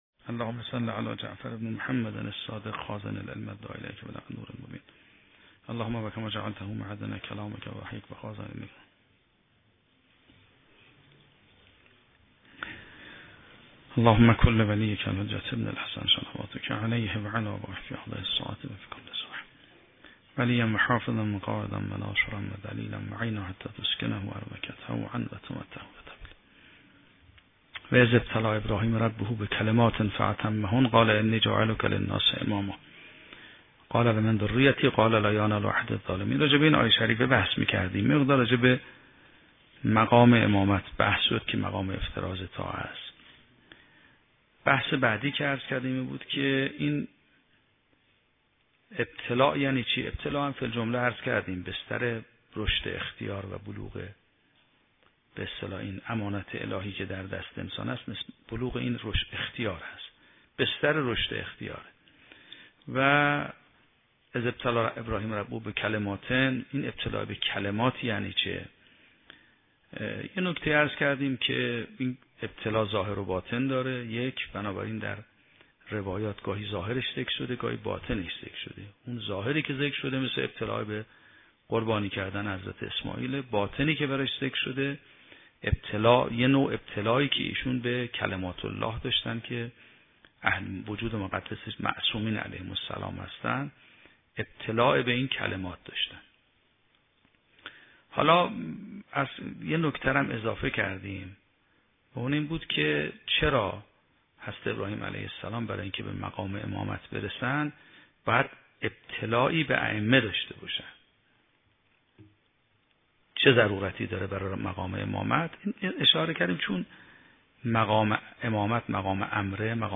شرح و بررسی کتاب الحجه کافی توسط آیت الله سید محمدمهدی میرباقری به همراه متن سخنرانی ؛ این بخش : بررسی ابتلائات الهی به کلمات عظیم - معنای کلمه در لغت و اصلاحات